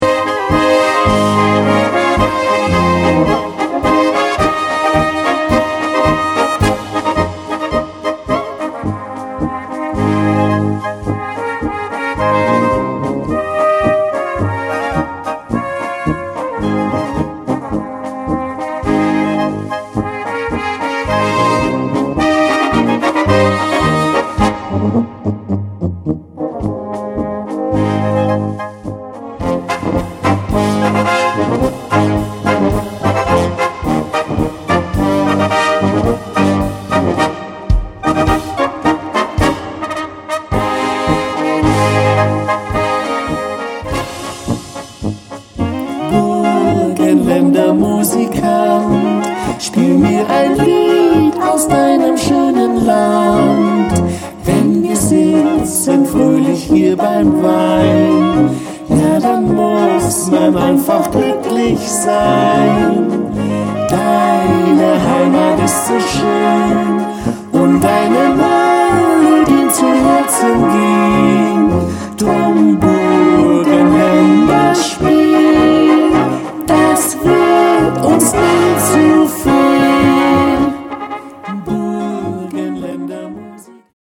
Gattung: Polka für Blasorchester
Besetzung: Blasorchester
eine lebendige Polka im böhmischen Stil
eingängige Melodien, ein markantes Basssolo